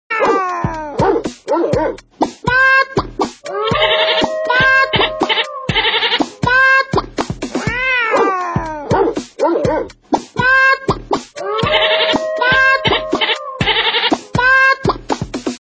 Kategori Dyr